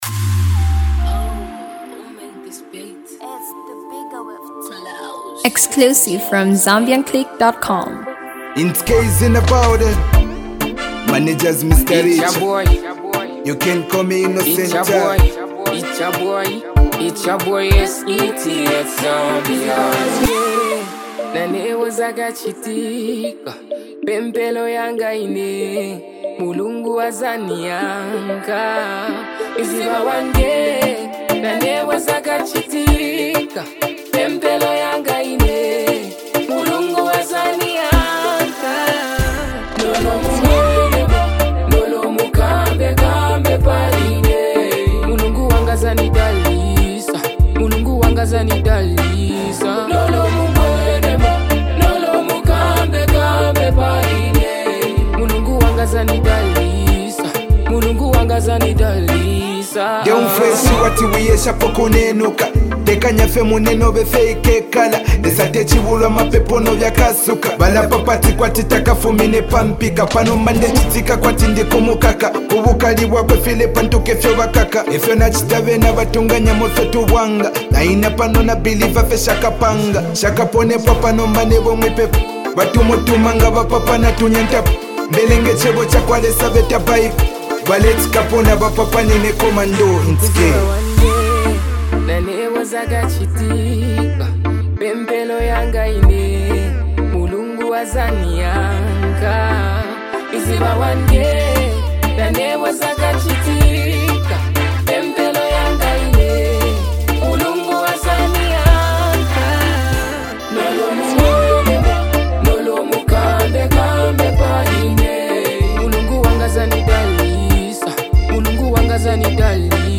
motivational song
male vocalist